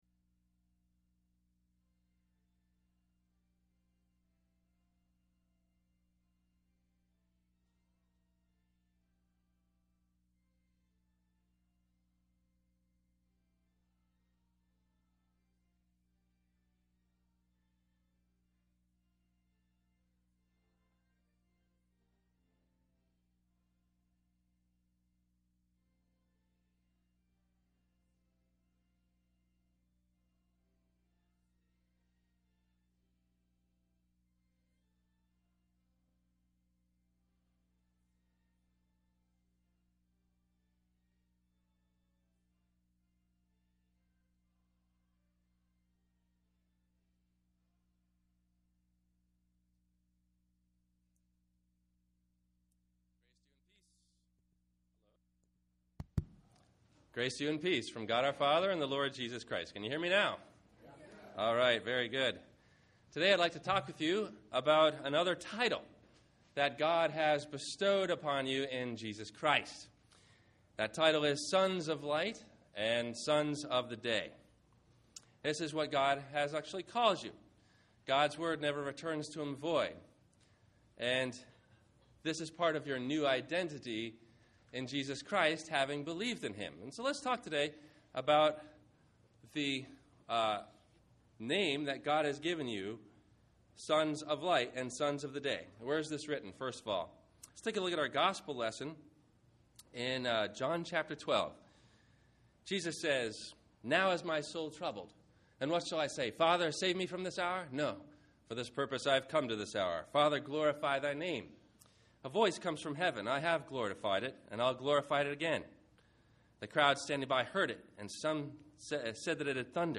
Sons of Light – Sermon – September 12 2010